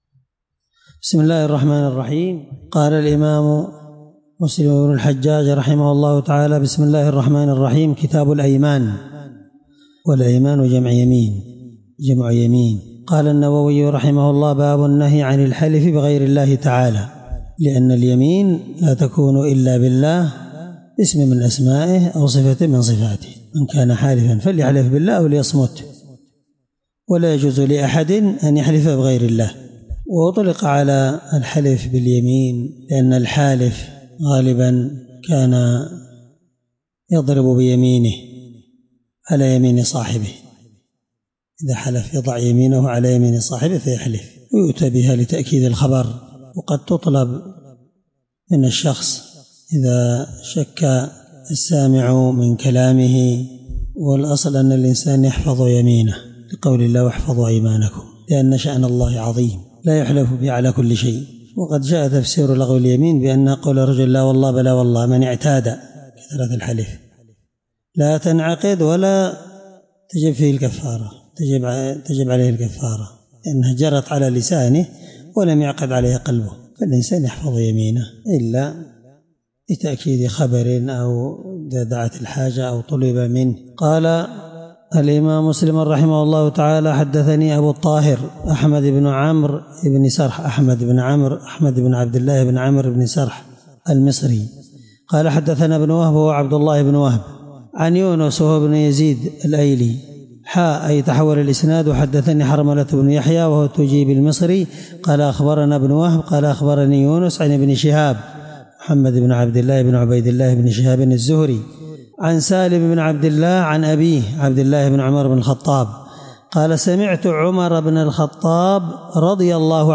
الدرس1من شرح كتاب الأيمان حديث رقم(1646) من صحيح مسلم